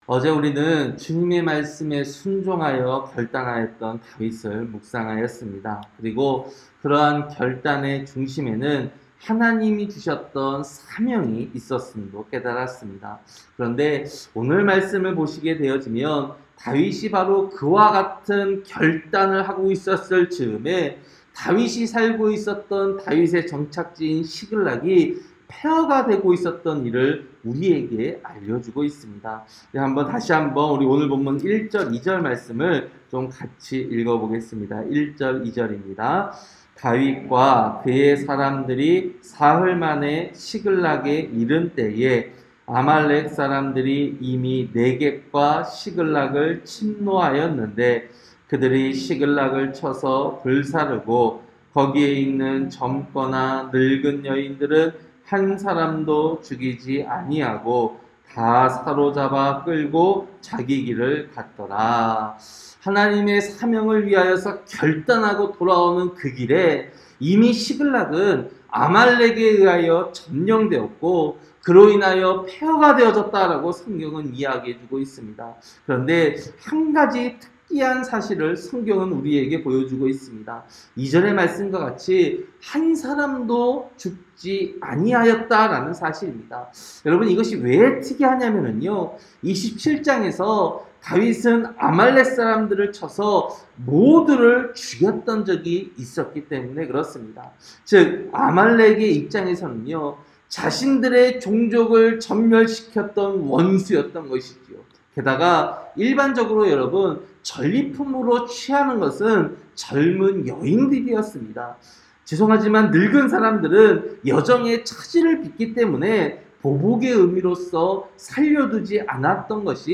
새벽설교-사무엘상 30장